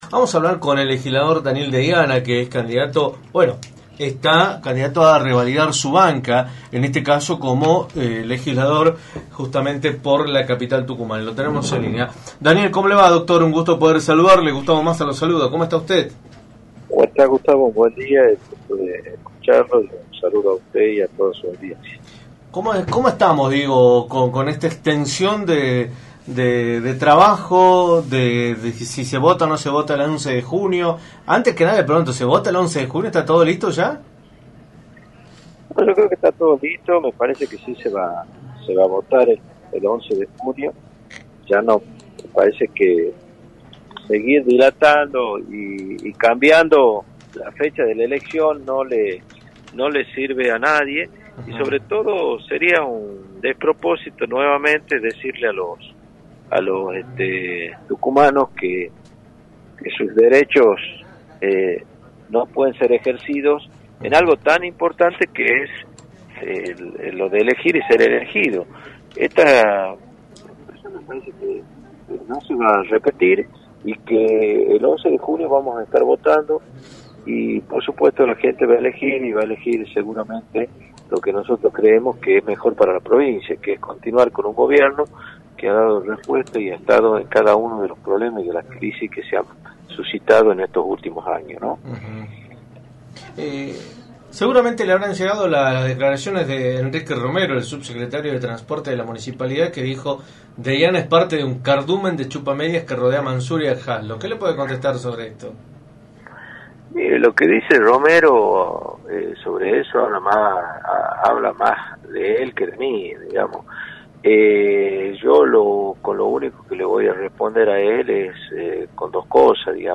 Daniel Deiana, Legislador y candidato a renovar su banca en la Legislatura de Tucumán, abordó en Radio del Plata Tucumán, por la 93.9, el panorama político y electoral de la provincia, luego de que se confirmara que los comicios se llevarán a cabo el 11 de junio.